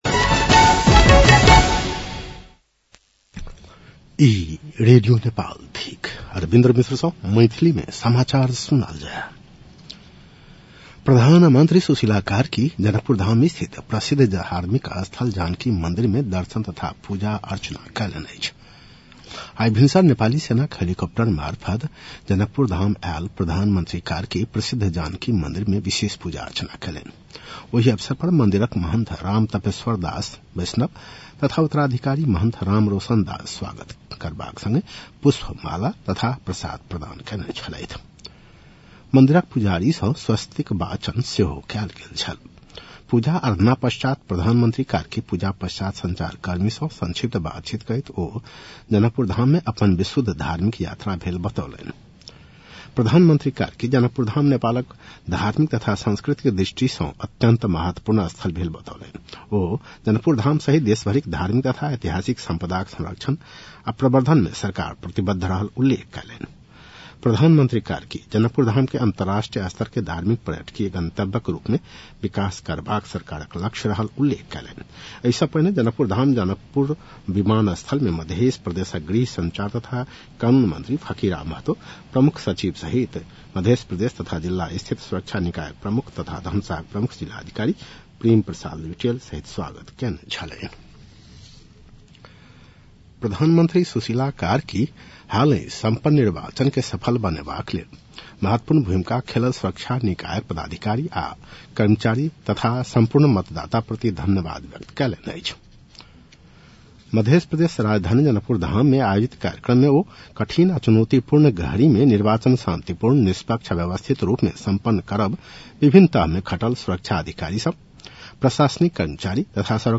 मैथिली भाषामा समाचार : ६ चैत , २०८२
Maithali-news-12-06.mp3